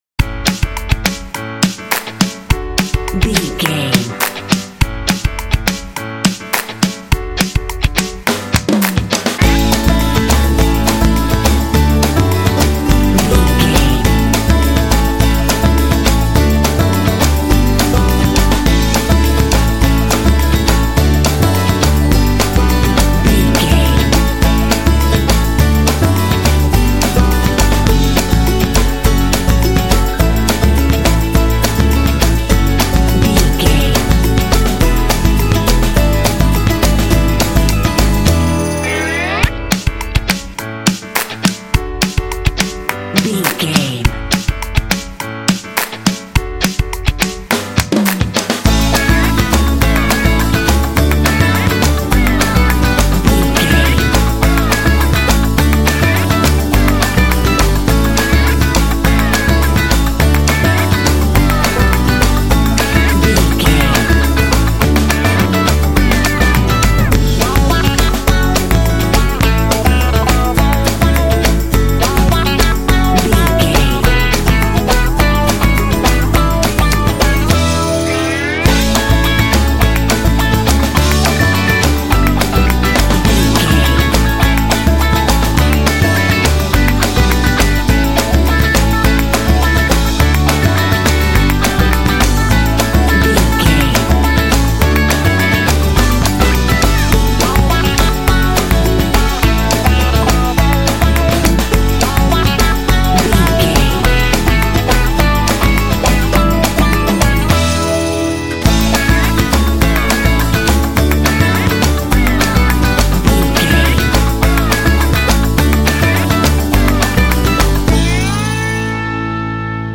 This tune is super cheerful and full of energy.
Ionian/Major
Fast
driving
bouncy
groovy
bright
bass guitar
electric guitar
drums
acoustic guitar
rock
pop
alternative rock
indie